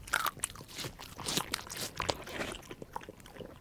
bdog_eat_6.ogg